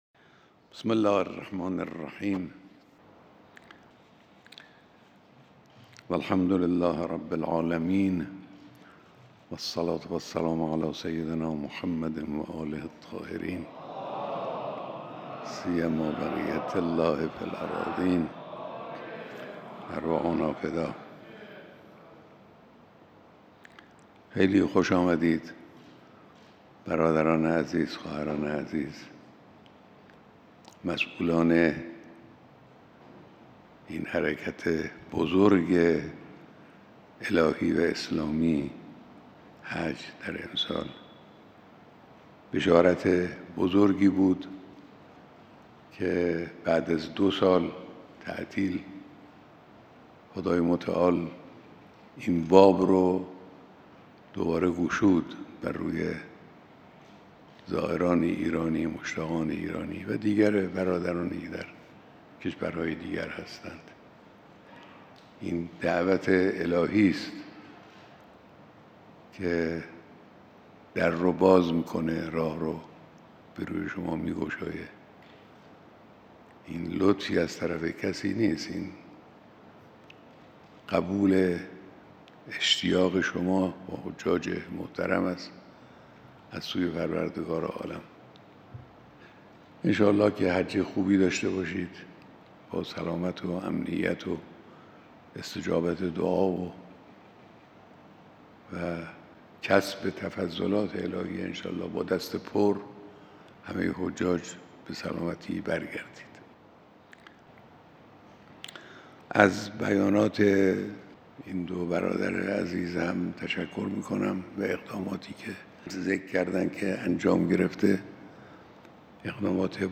بیانات دیدار جمعی از دست اندرکاران و کارگزاران حج